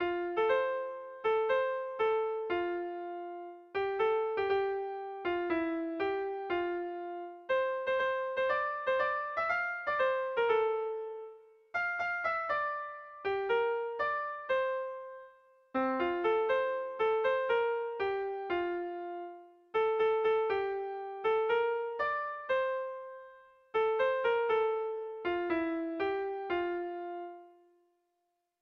Bertso melodies - View details   To know more about this section
Kontakizunezkoa
ABDE